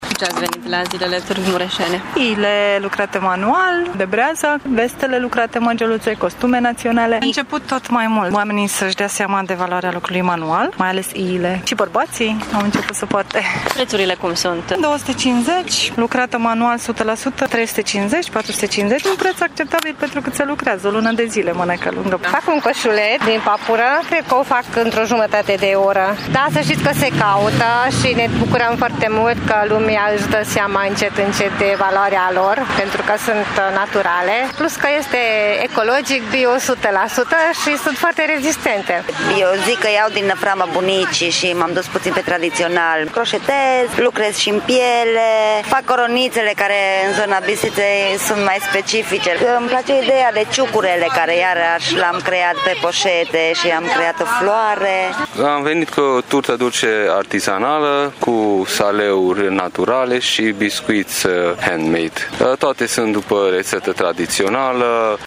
Artiștii populari au venit cu cele mai noi creații de artizanat și artă populară, artă ceramică, plastică, pe sticlă și în lemn și spun că se bucură de tot mai mare apreciere din partea vizitatorilor: